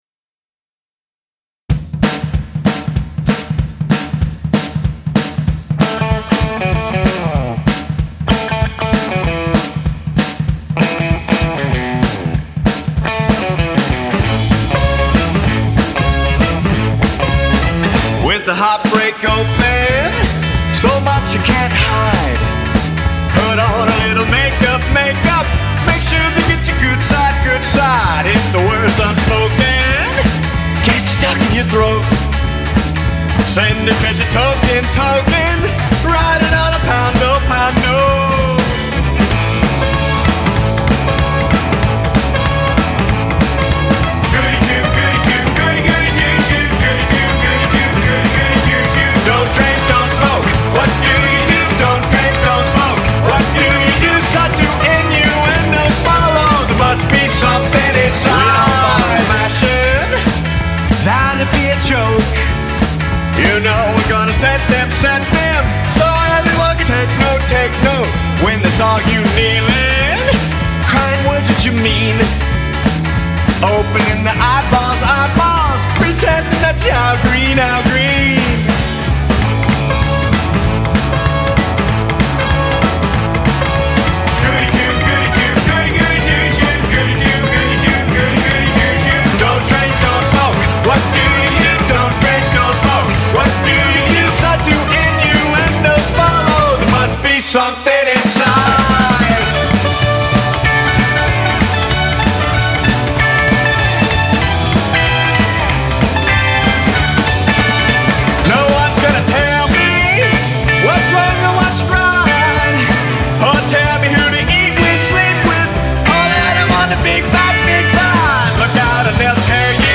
Vocals
Bass
Drums
Sax
Trumpet
Backing Vocals